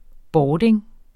Udtale [ ˈbɒːdeŋ ]